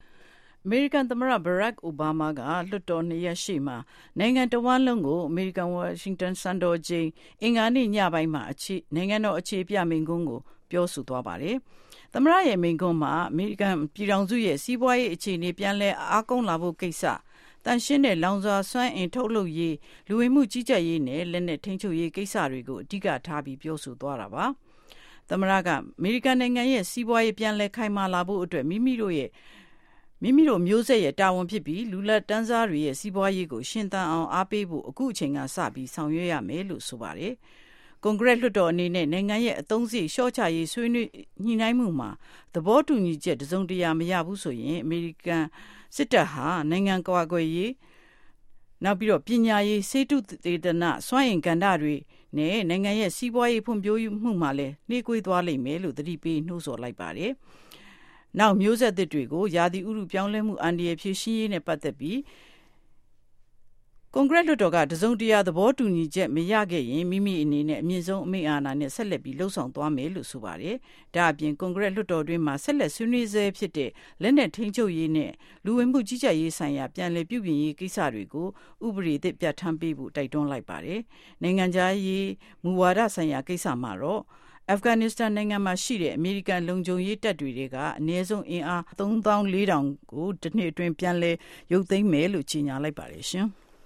သမ္မတအိုဘားမား မိန့်ခွန်း